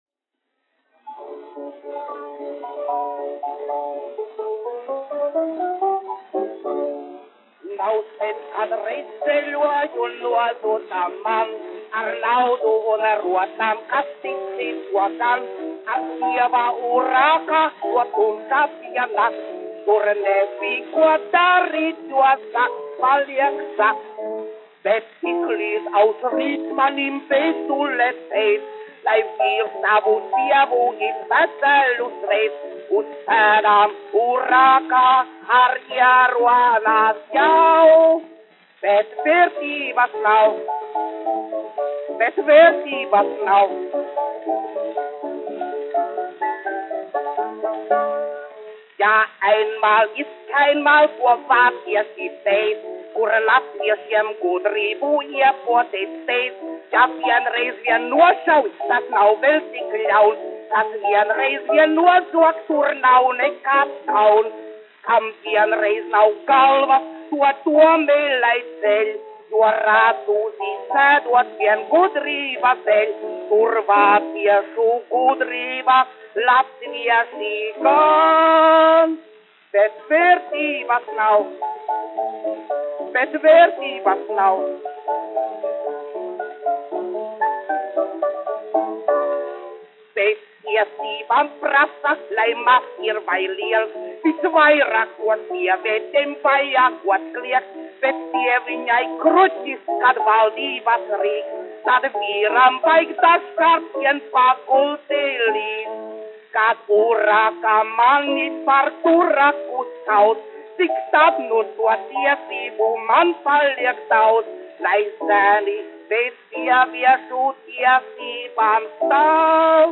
Dons Zesars : kupleja
Rutku Tēvs, 1886-1961, izpildītājs
1 skpl. : analogs, 78 apgr/min, mono ; 25 cm
Operas--Fragmenti, aranžēti
Latvijas vēsturiskie šellaka skaņuplašu ieraksti (Kolekcija)